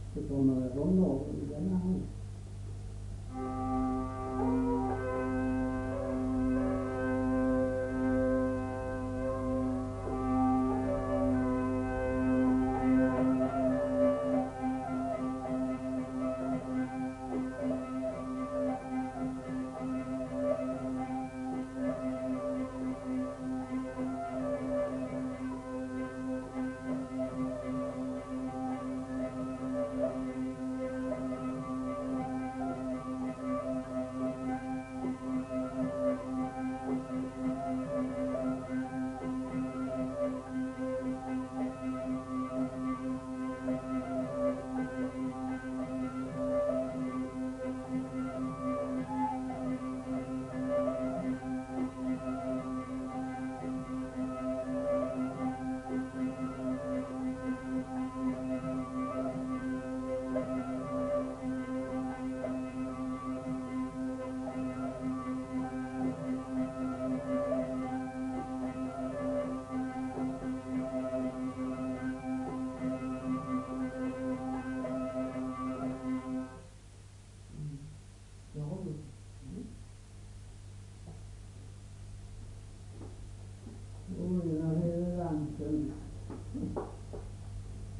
Lieu : Lencouacq
Genre : morceau instrumental
Instrument de musique : vielle à roue
Danse : rondeau